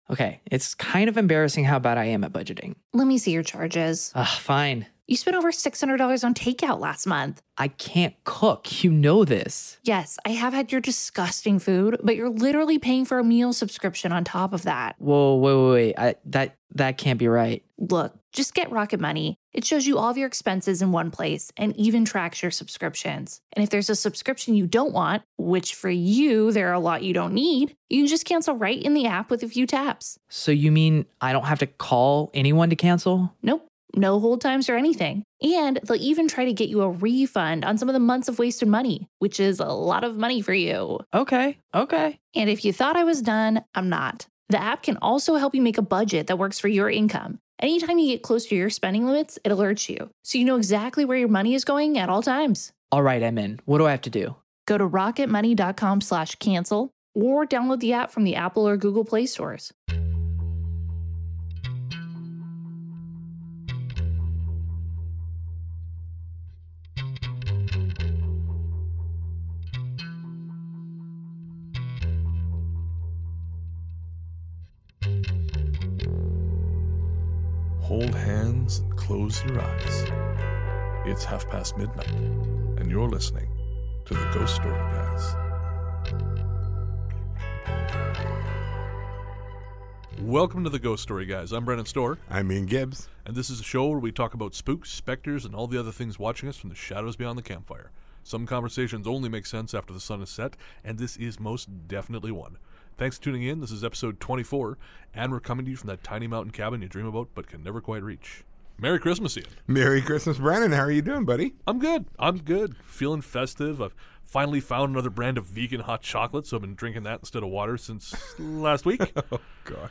From a hot-off-the-presses story about a seemingly impossible occurrence in a hospital to a sharp-toothed Santa, to mysterious chimes and bells signaling from beyond, we have a little something for everyone . We're also high on sugar so there's a lot of bad language, a conversation about the nature of God that goes to a strange place, and possibly the longest outtakes sequence so far.